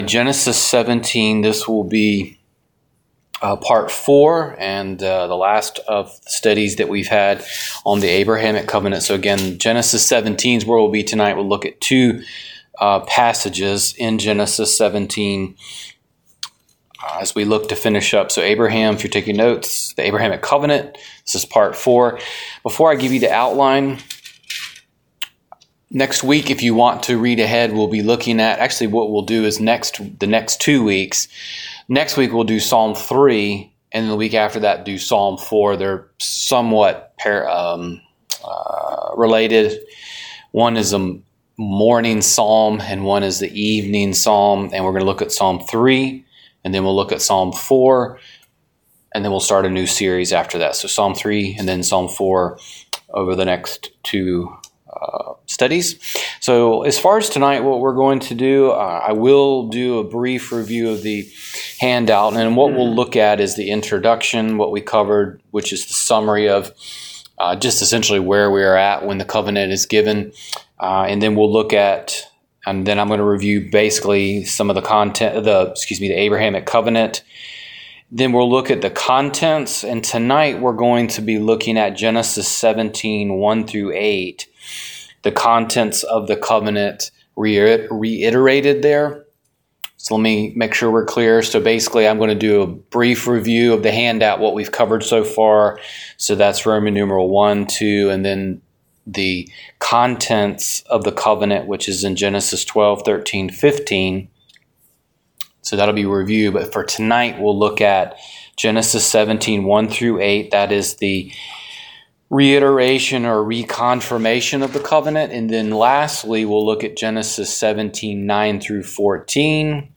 Download Download The Abrahamic Covenant - Part 4 Wed. Night Bible Study The Colossian Hymn - Part 2 Wed.